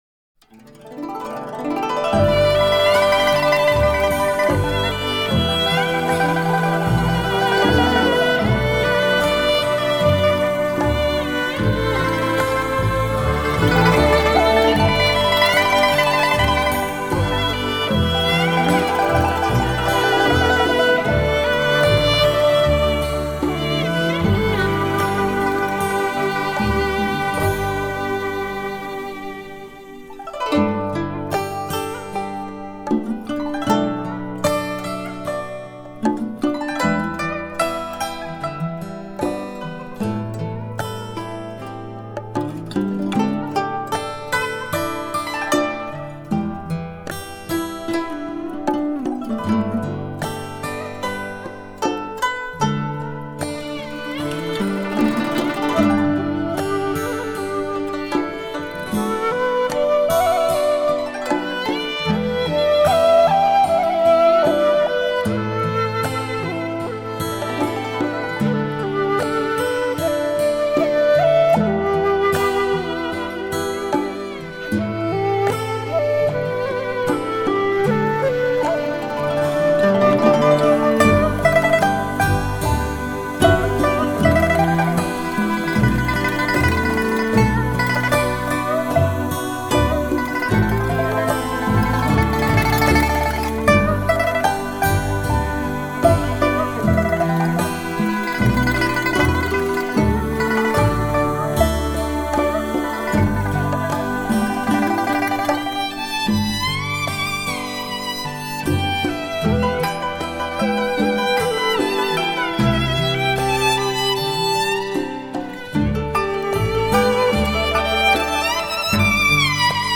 中国最著名的女子器乐组合 国宝级的靓丽女子乐队
中国与世界的音乐对话 中西方乐器的完美融合